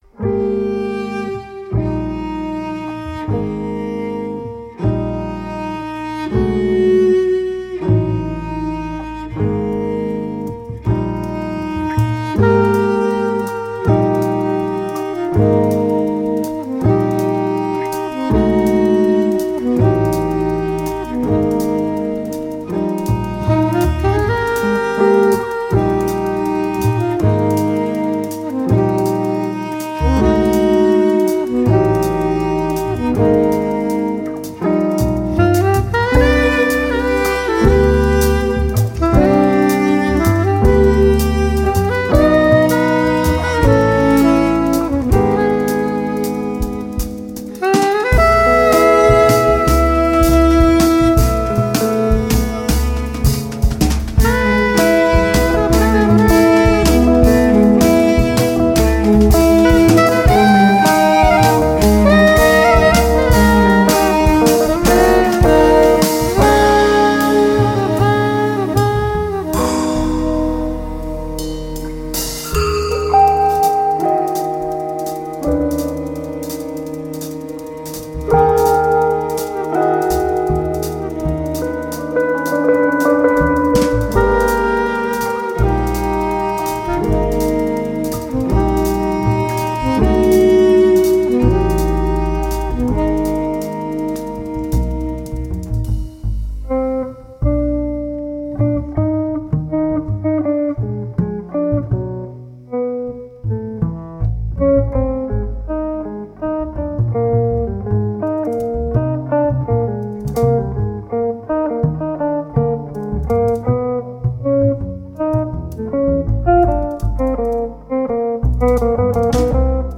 Guitar
Soprano Sax
cello
Double Bass
Drums